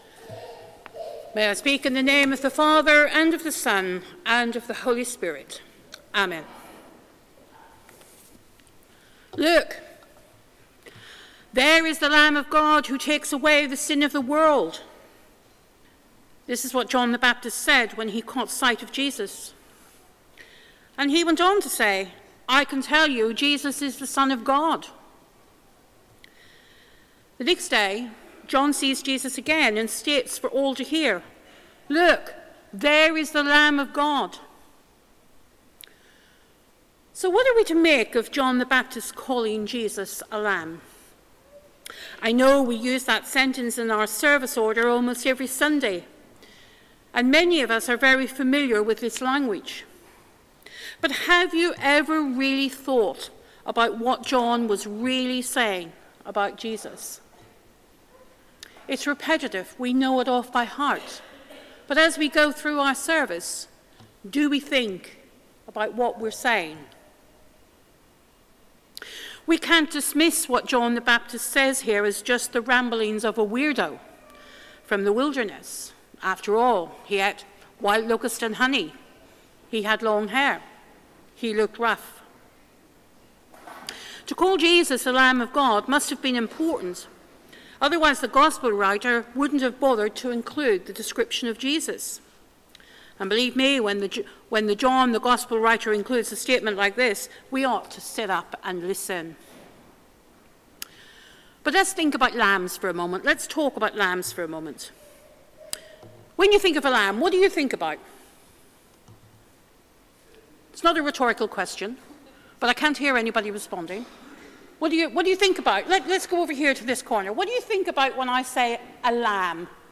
Sermon: Jesus; the Sacrificial Lamb | St Paul + St Stephen Gloucester